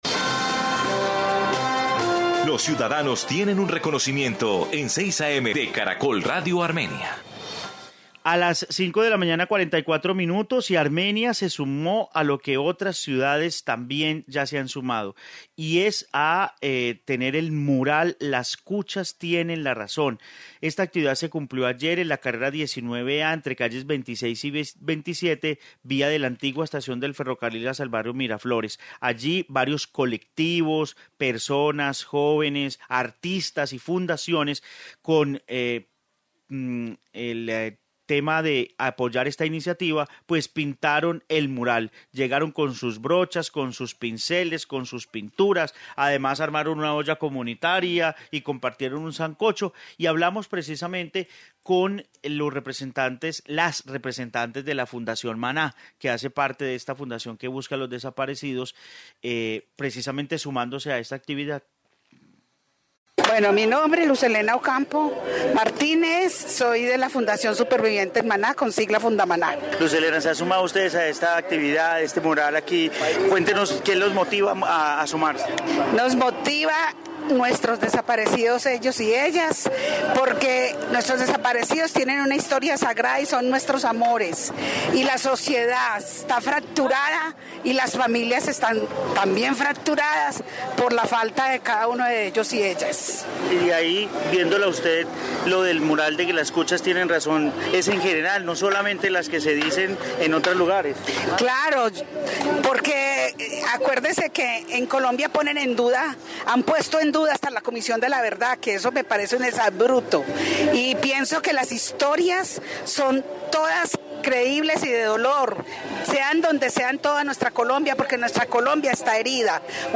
Informe "las cuchas tienen la razón"